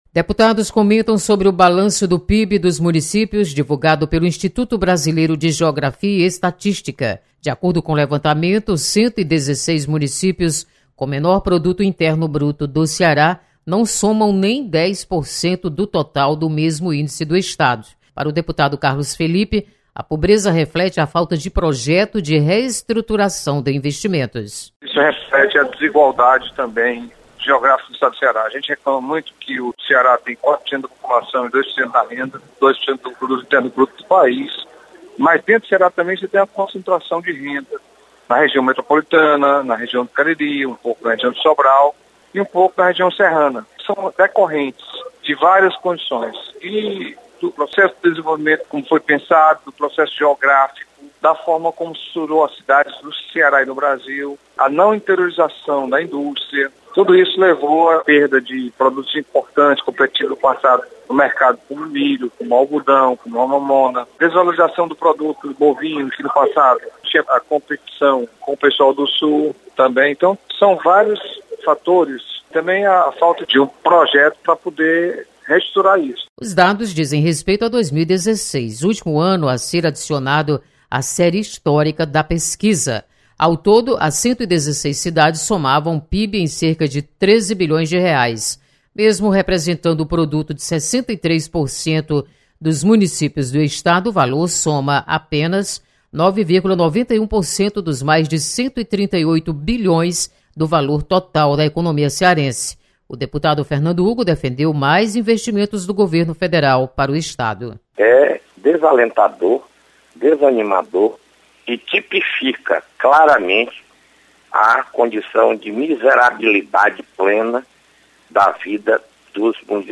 Deputados comentam sobre balanço do PIB Municipal.